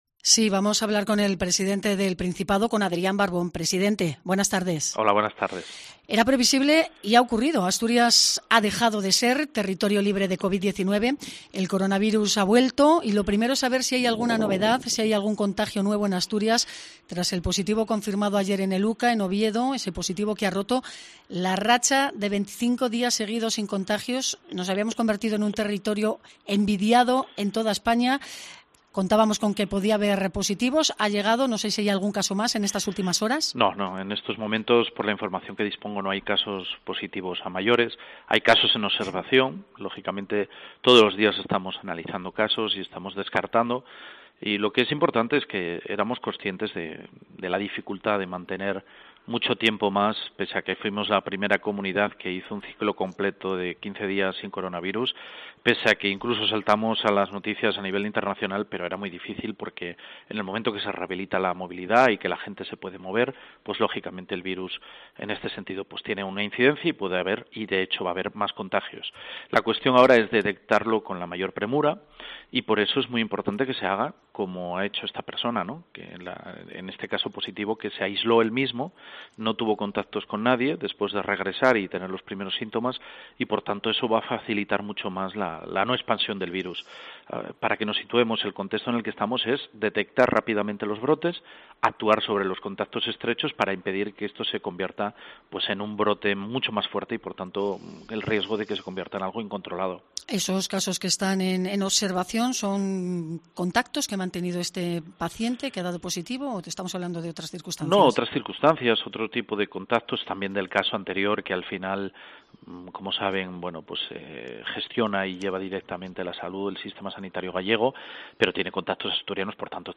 Entrevista con el presidente del Principado en COPE Asturias